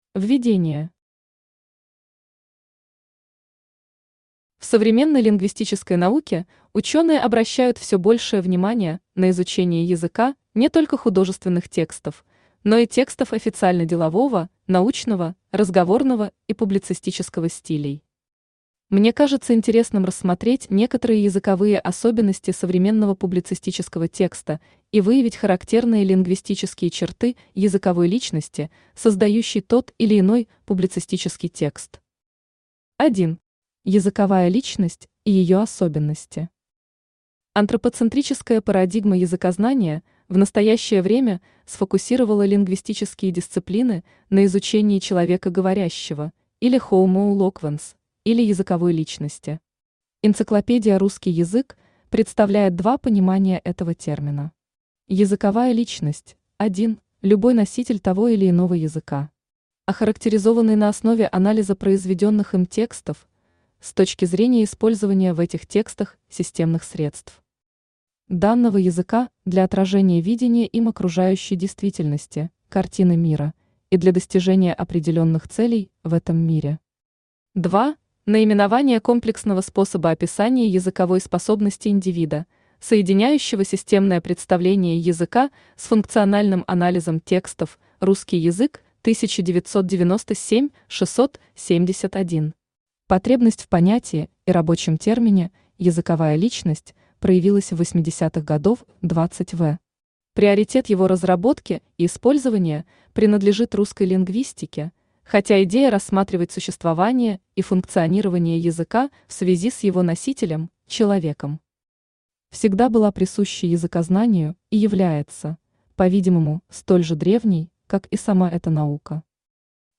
Aудиокнига Языковая личность автора публицистического текста Автор Надежда Игоревна Соколова Читает аудиокнигу Авточтец ЛитРес.